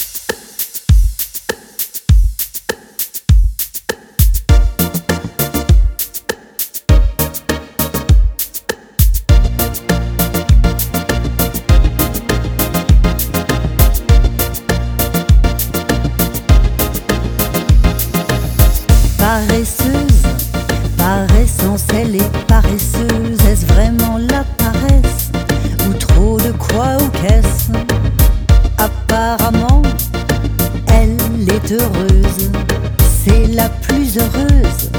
French Pop
Жанр: Поп музыка